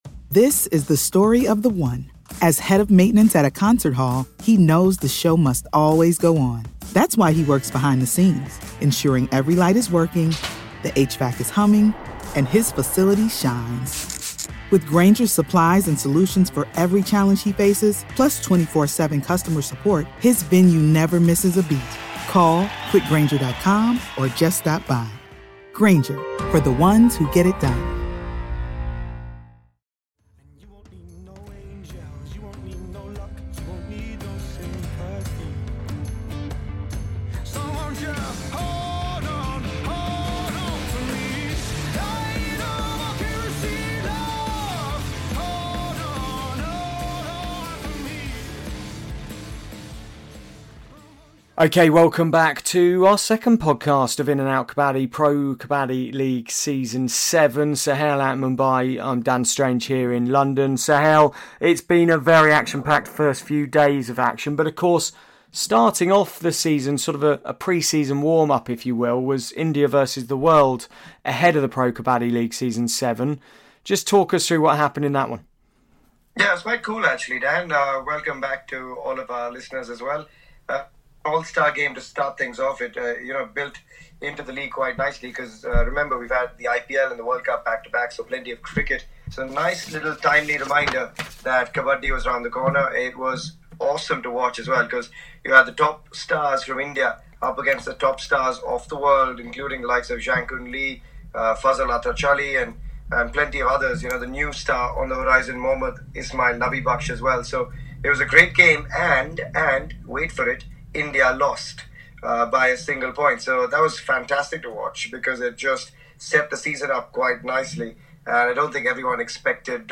This time: Reviews of the first few matches Why UMumba vs JPP is HUGE!